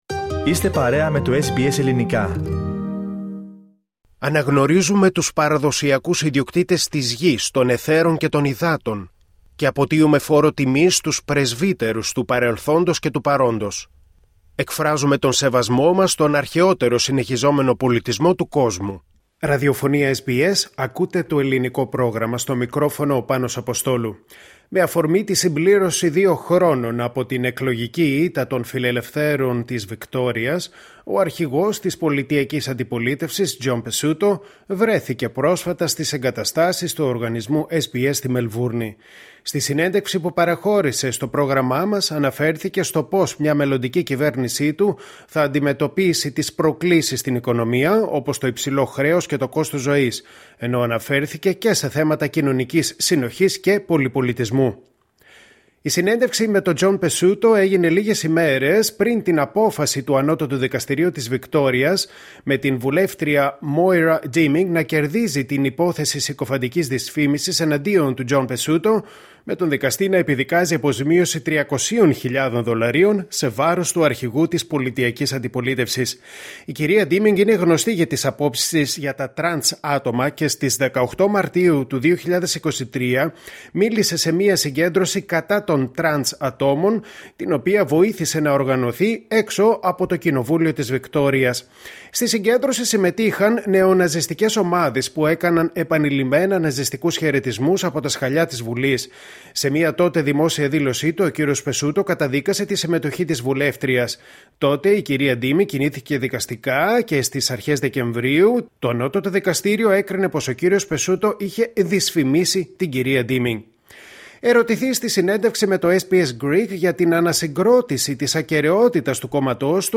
Victorian Opposition Leader John Pesutto, at SBS Melbourne offices / SBS Greek